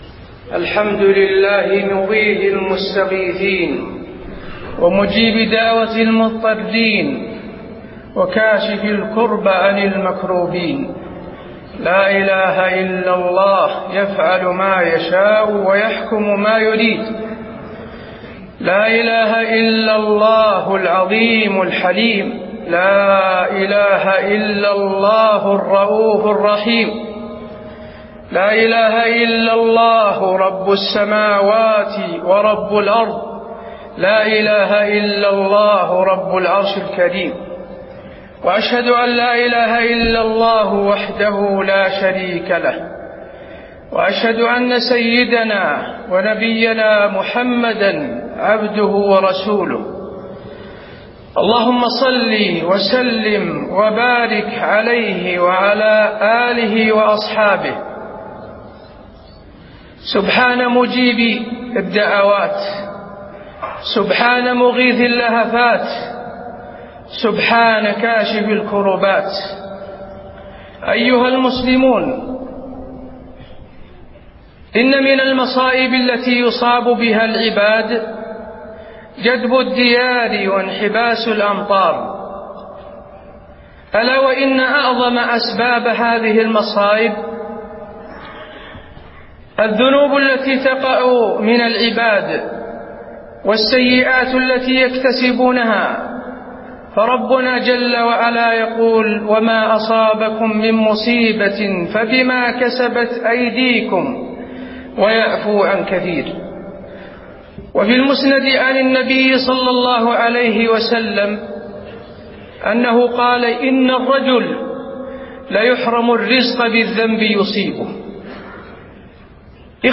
خطبة الاستسقاء - المدينة- الشيخ حسين آل الشيخ
المكان: المسجد النبوي